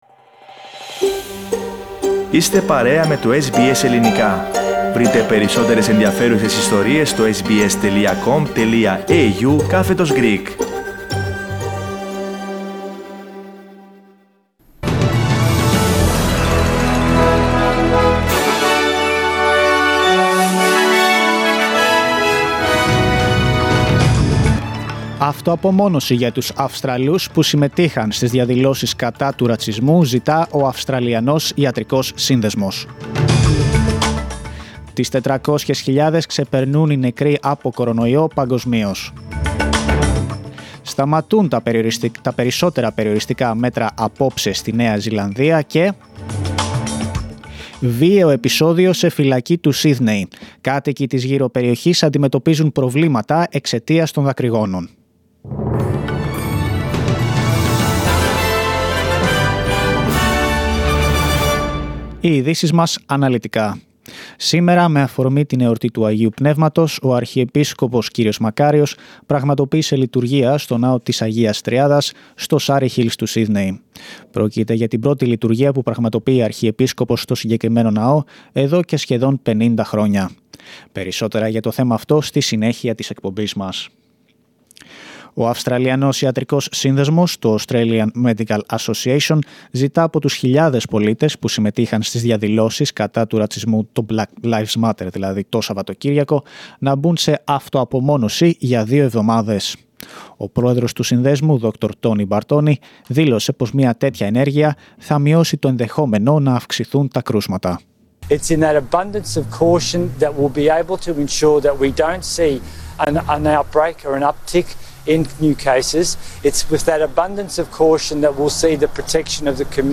News from Australia, Greece, Cyprus and the world in the News Bulletin of Monday 8th of June.